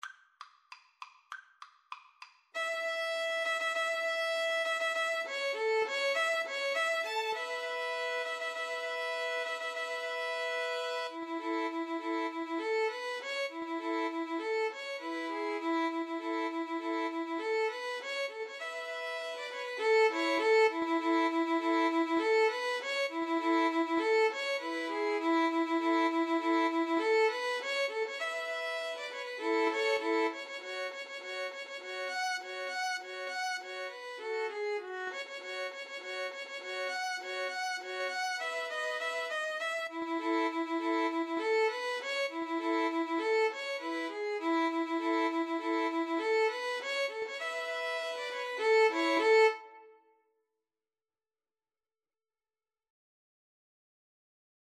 Violin 1Violin 2Cello
Presto =200 (View more music marked Presto)
Classical (View more Classical 2-Violins-Cello Music)